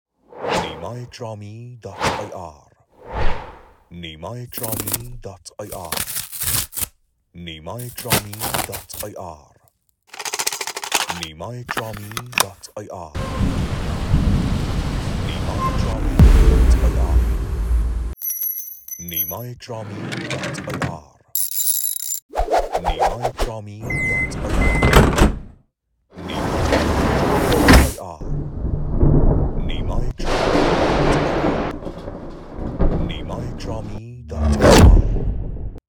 مجموعه‌ای از جلوه‌های صوتی برای طراحی صدا در تدوین عروسی شامل:
• Whoosh: افکت‌های جابجایی سریع
• Swirl: صداهای چرخشی و پویا
• Rachet: افکت‌های مکانیکی
نمونه های افکت صوتی (ساند دیزاین)
نمونه-افکت-های-صوتی.mp3